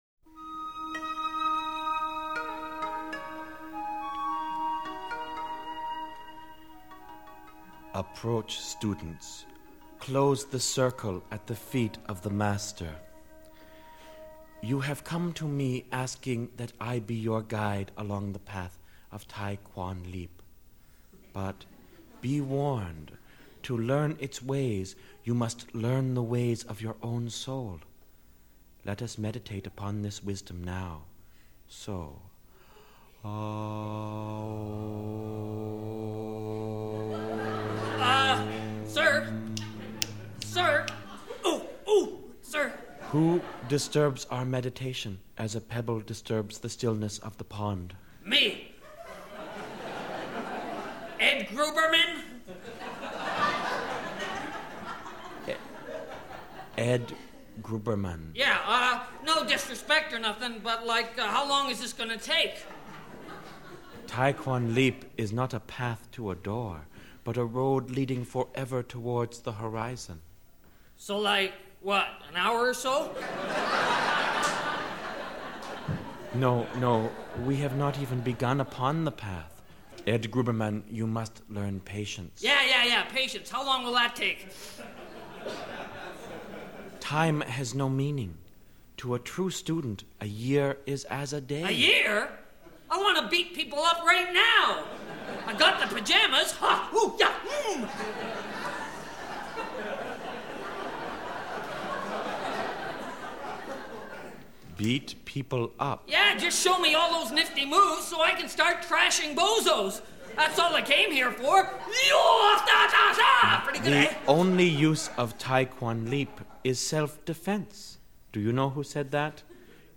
Here, have some novelty records: